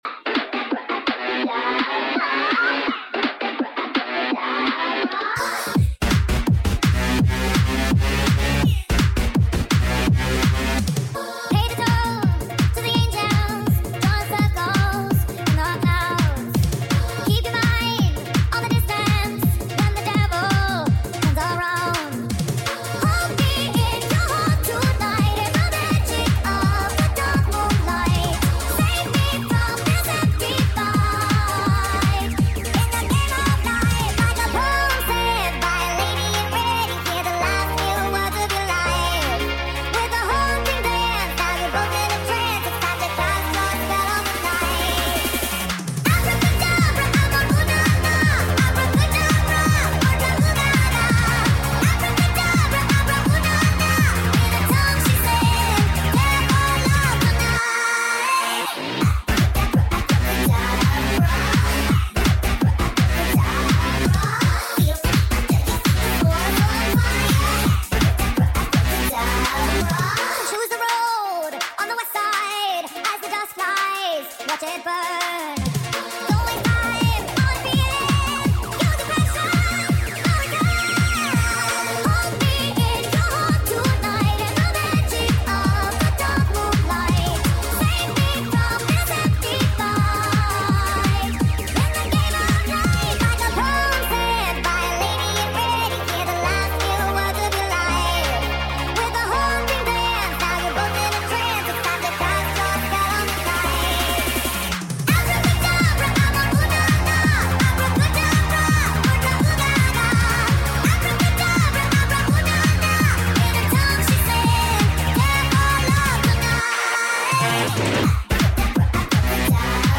NIGHTCORE🌙🎧 this is a remake